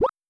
Water8.wav